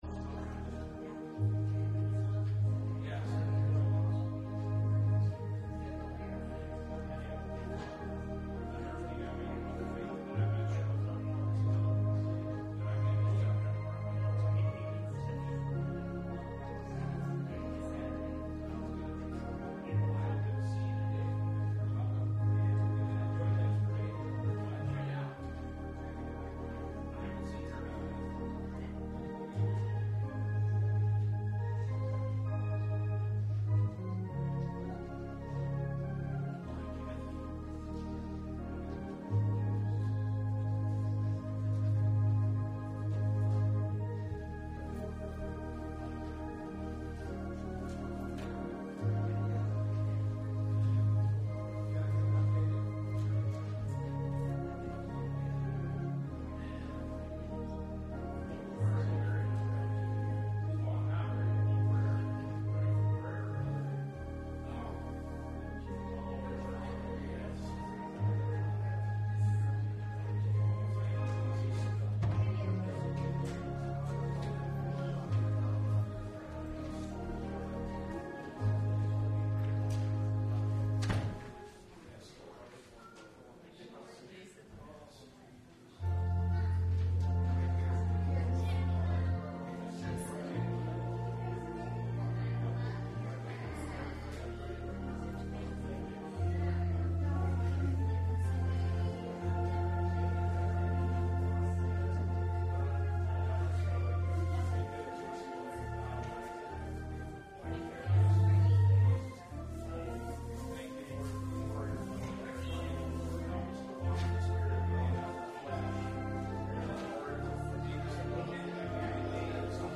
Sunday Morning Service Topics